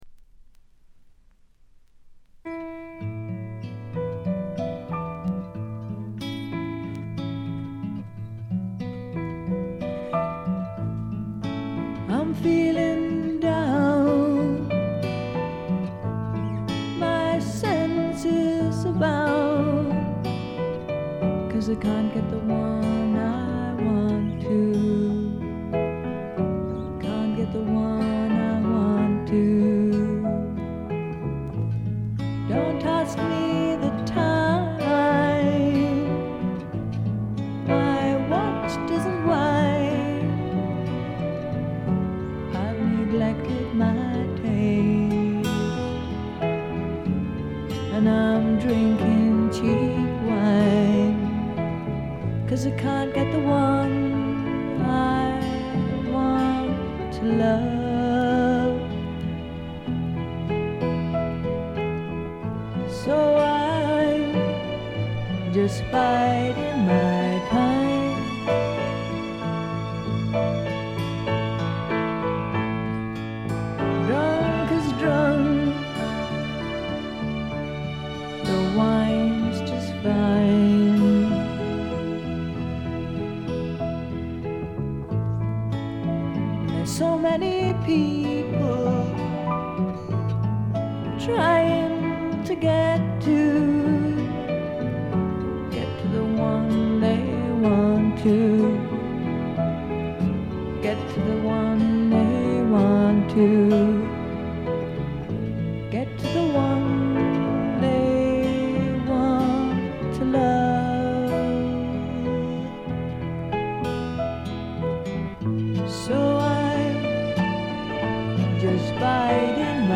ほとんどノイズ感無し。
音の方はウッドストック・サウンドに英国的な香りが漂ってくるという、この筋の方にはたまらないものに仕上がっています。
試聴曲は現品からの取り込み音源です。